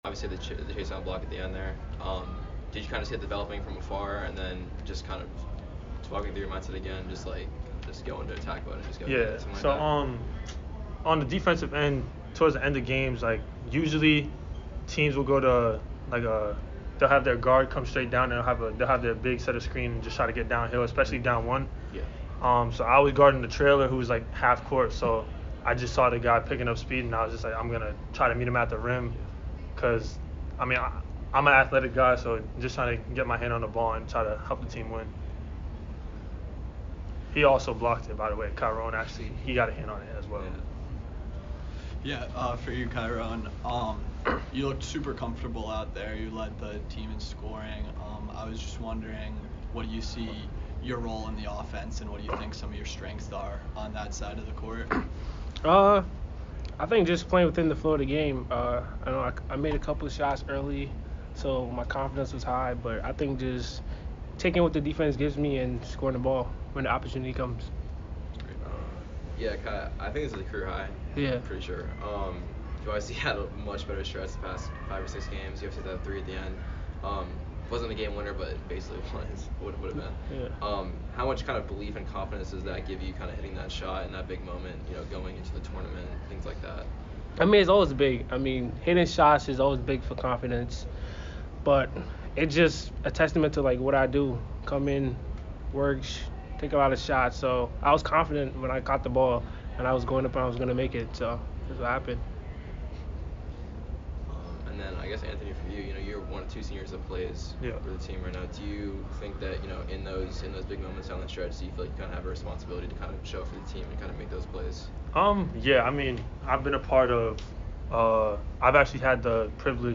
Loyola Maryland Postgame Interview